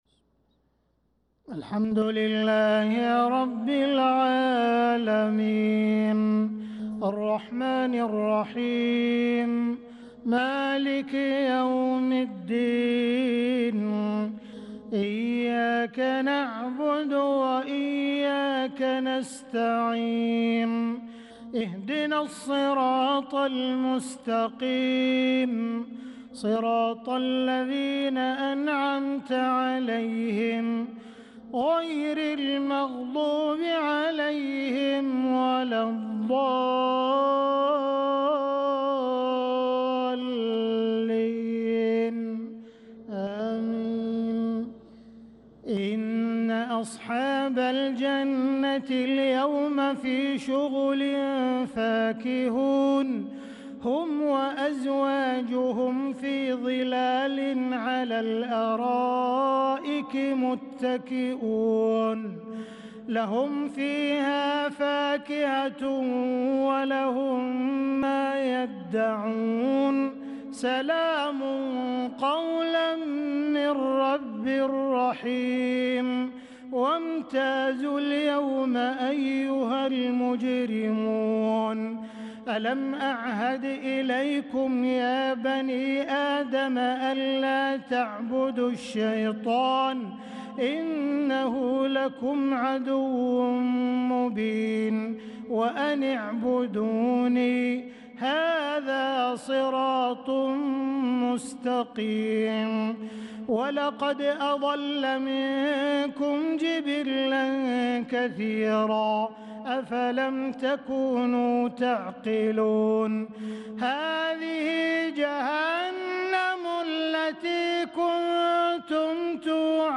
صلاة العشاء للقارئ عبدالرحمن السديس 12 ذو القعدة 1445 هـ
تِلَاوَات الْحَرَمَيْن .